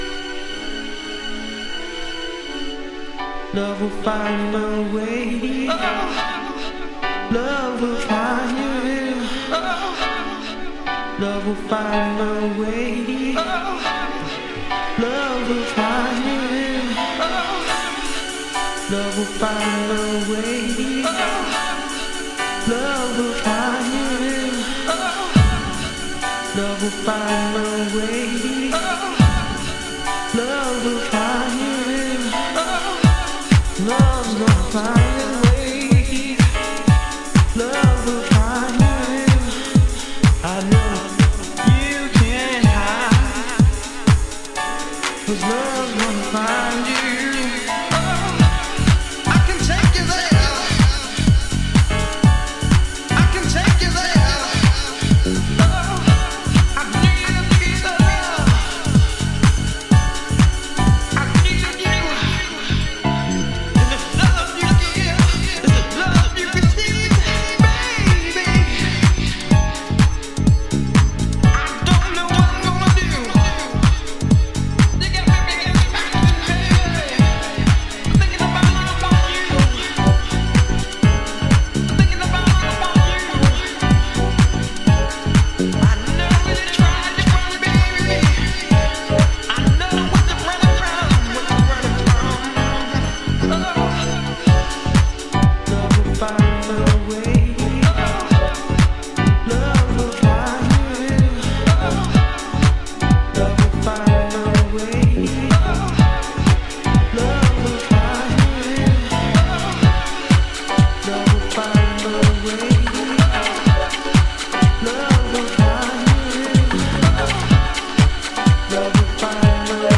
グルーヴは断じてタフであります。
Vocal Mix
Early House / 90's Techno